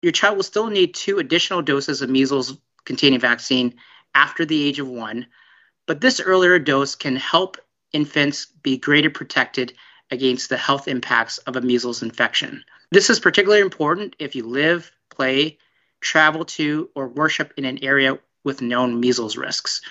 During a media call on Tuesday, they said the region is an outbreak situation which requires some extra precaution for the youngest population in the area.